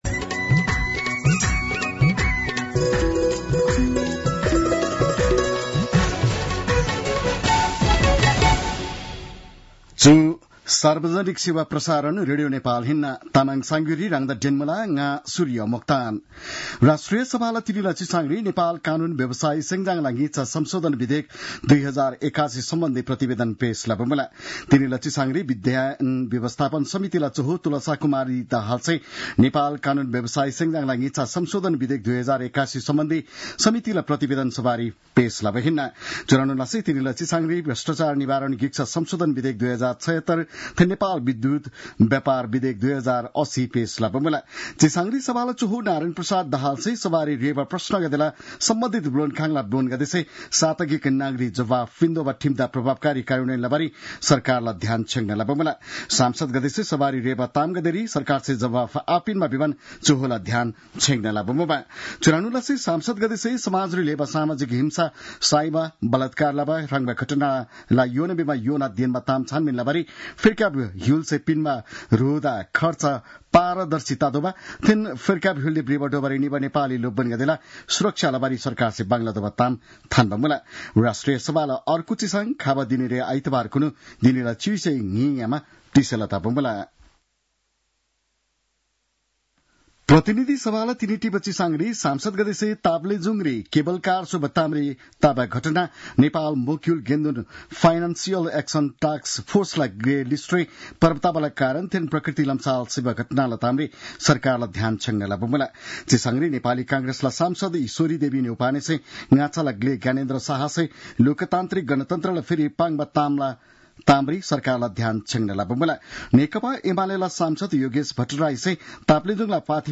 An online outlet of Nepal's national radio broadcaster
तामाङ भाषाको समाचार : १२ फागुन , २०८१